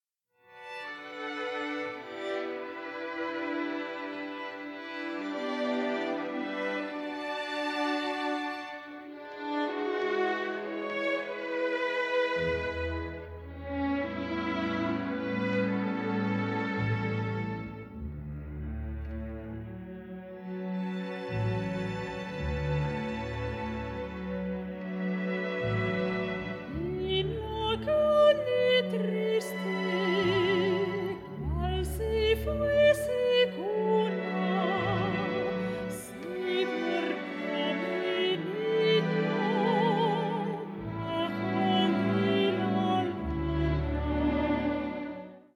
mezzo-soprano
(first recording of orchestral version)